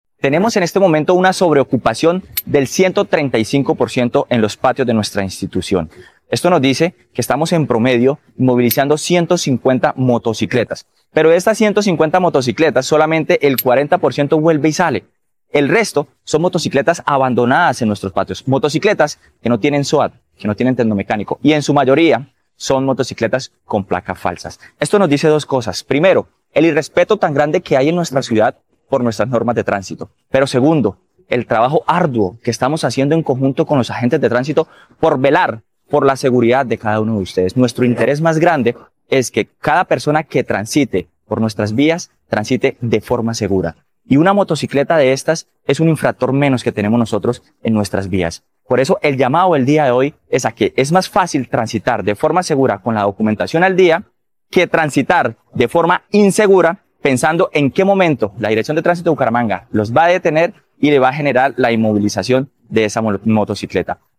Jhair Manrique, Director de Tránsito de Bucaramanga